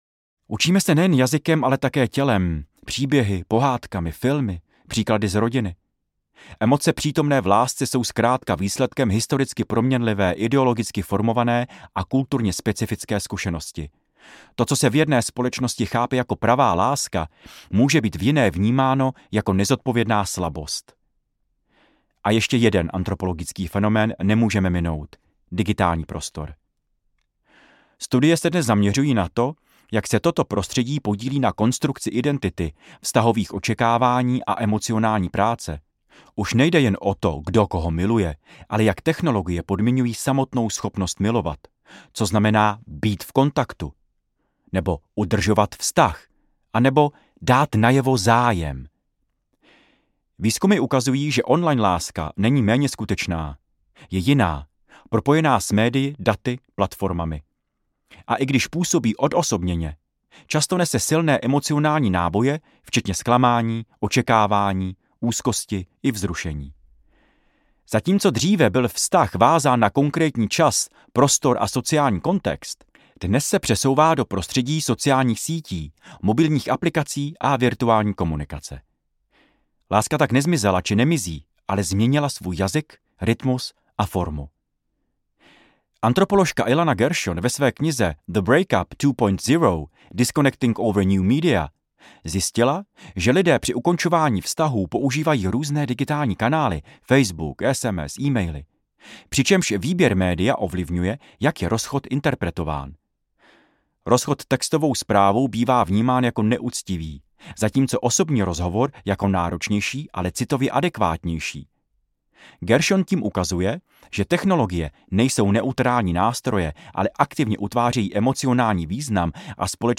Příběh lásky audiokniha
Ukázka z knihy
Vyrobilo studio Soundguru.